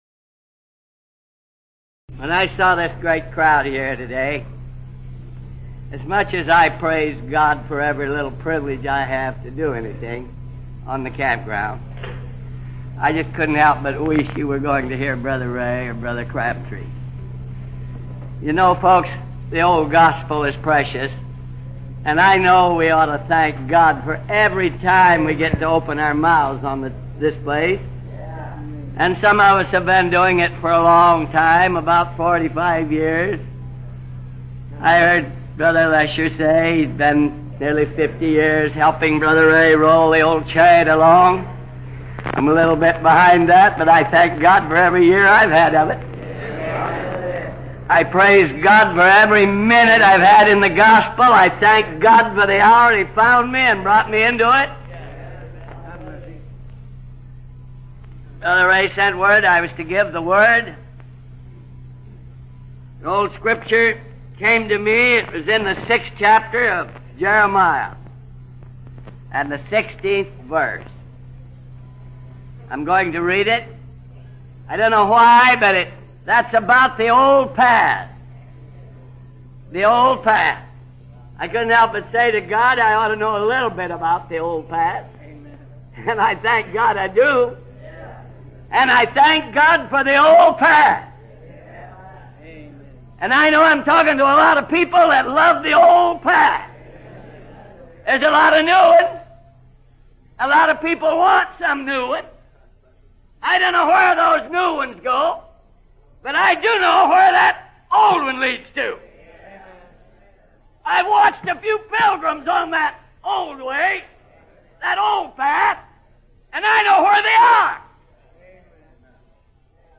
This message was preached at Portland Camp Meeting 1958, and published in our magazine in August of 1994.